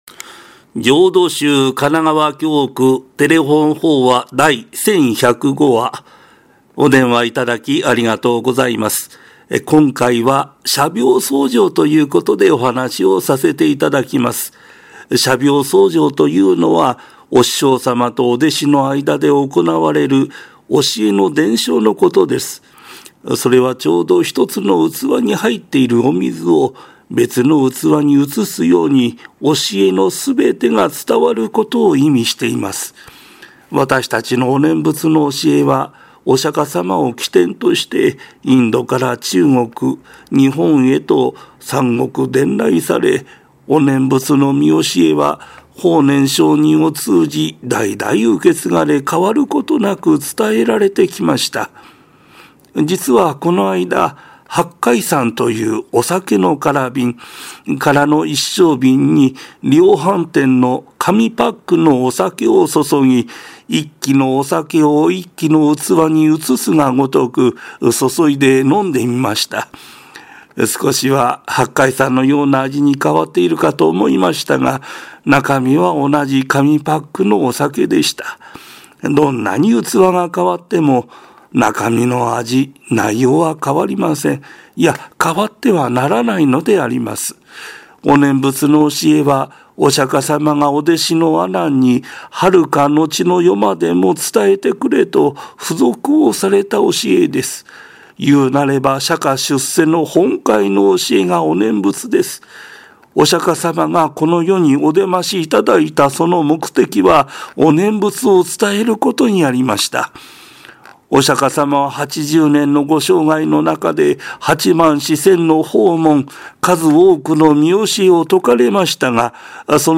テレホン法話
法話